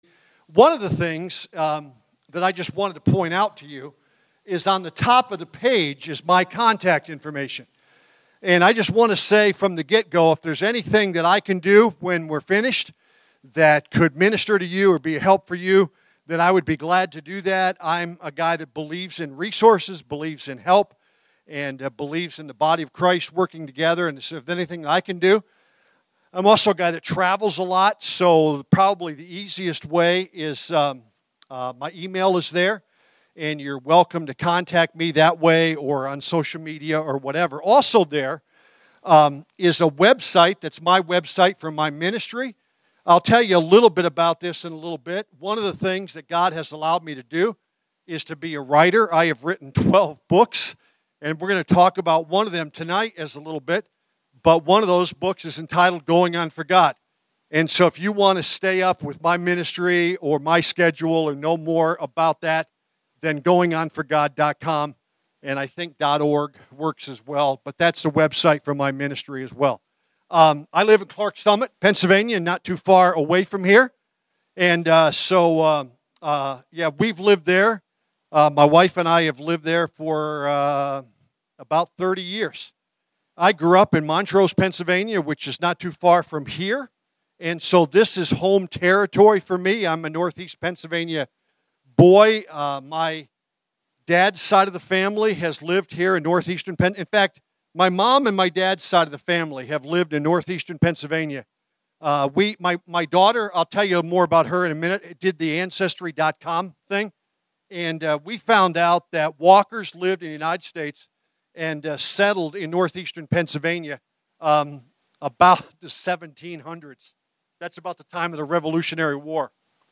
Friday evening message at THAW March 6, 2020.